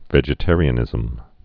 (vĕjĭ-târē-ə-nĭzəm)